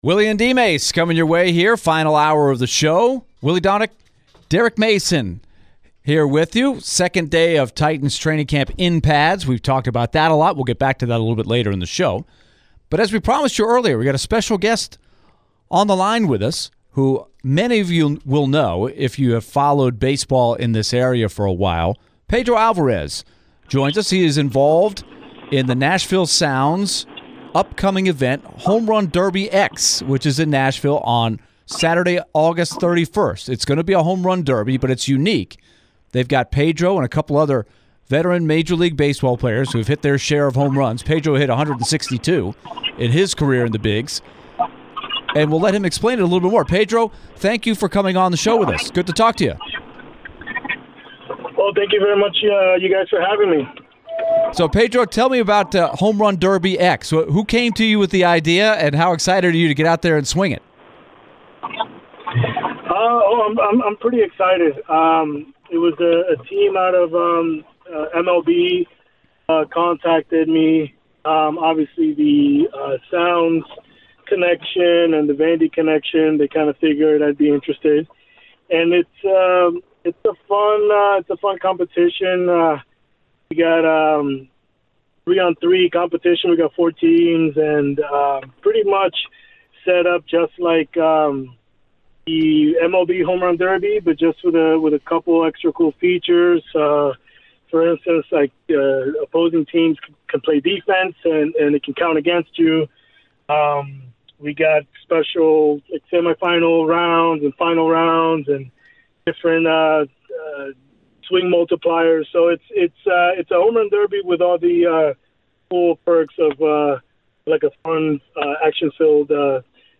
The guys chatted with Pedro Álvarez and discussed the upcoming Home Run Derby X. Pedro also talked about his time in Nashville with Vanderbilt and being coached by Tim Corbin.